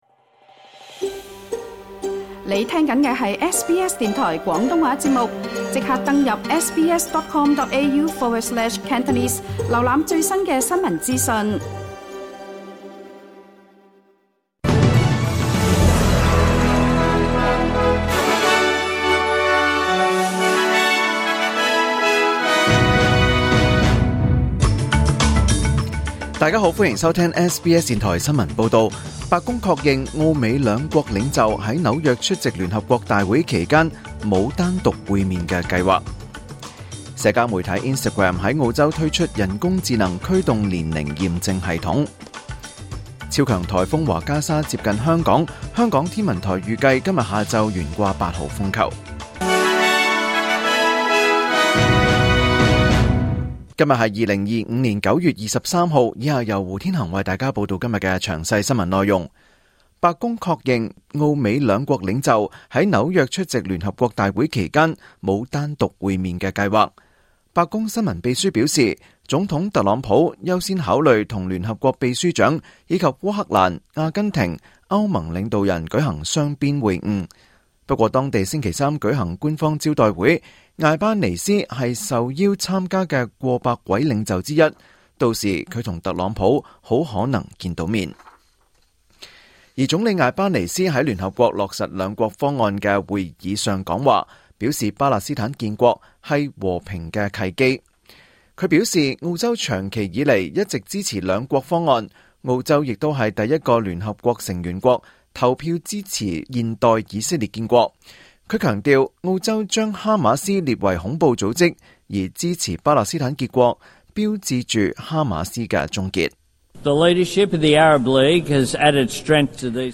2025 年 9 月 23 日 SBS 廣東話節目詳盡早晨新聞報道。